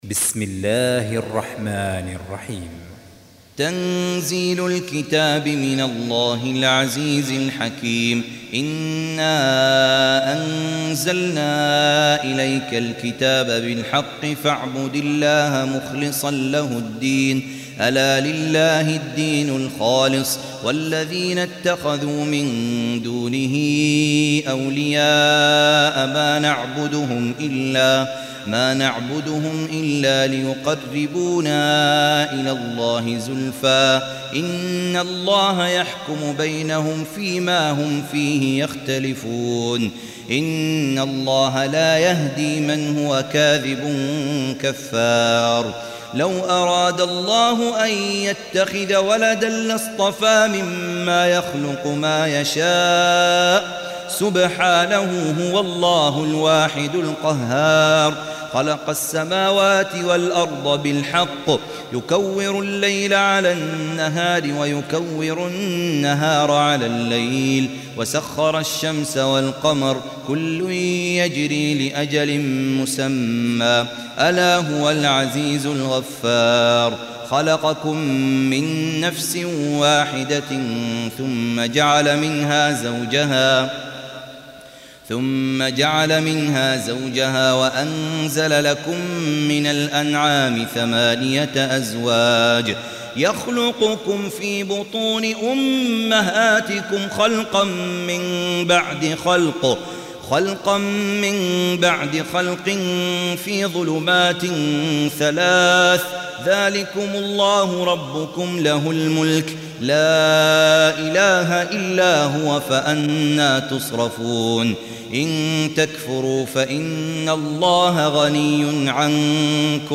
Surah Repeating تكرار السورة Download Surah حمّل السورة Reciting Murattalah Audio for 39. Surah Az-Zumar سورة الزمر N.B *Surah Includes Al-Basmalah Reciters Sequents تتابع التلاوات Reciters Repeats تكرار التلاوات